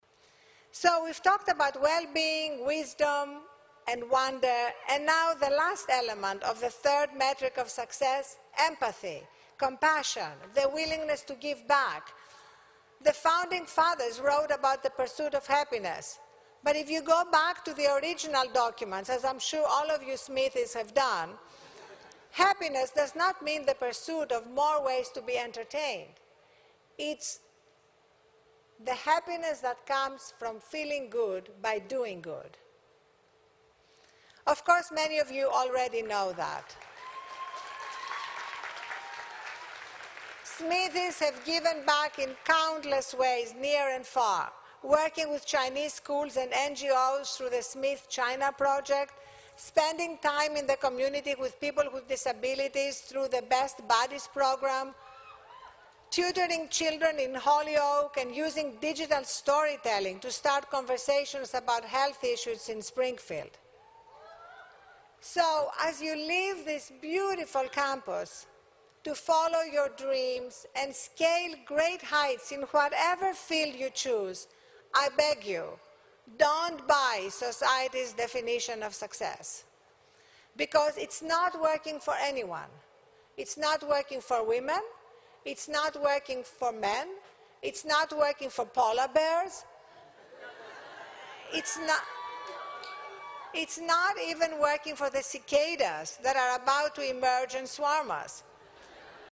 在线英语听力室公众人物毕业演讲第323期:阿丽安娜.哈芬顿2013史密斯学院(13)的听力文件下载,《公众人物毕业演讲》精选中西方公众人物的英语演讲视频音频，奥巴马、克林顿、金庸、推特CEO等公众人物现身毕业演讲专区,与你畅谈人生。